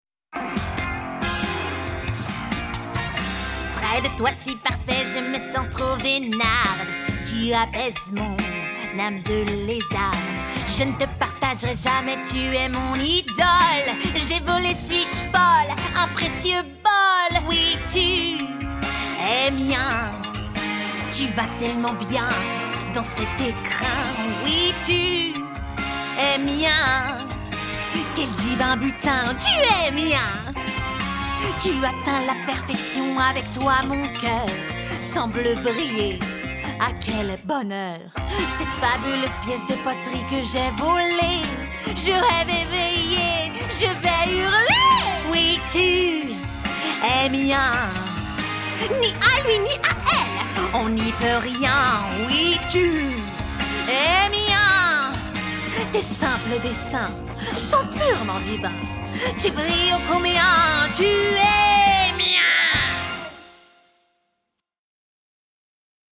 Chanteuse
8 - 30 ans - Mezzo-soprano